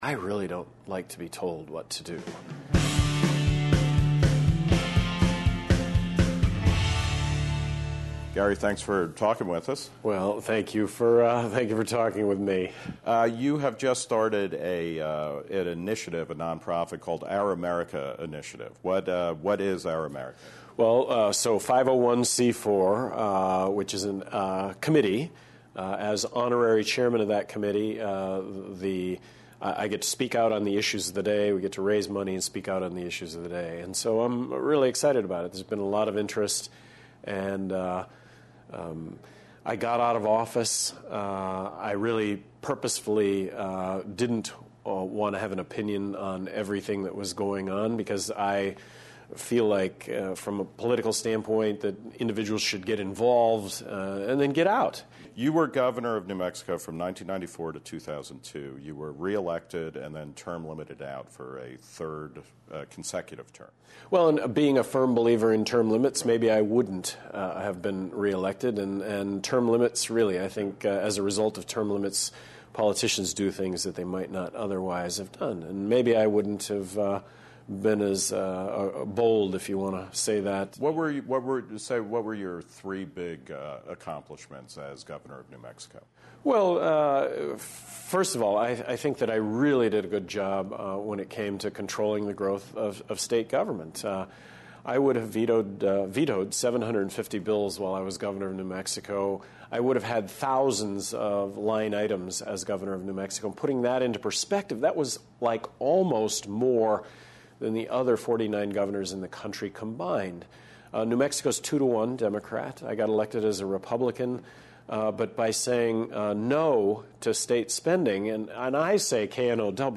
Note: This is a condensed version of a longer interview.